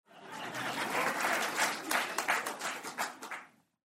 aplausositcom.mp3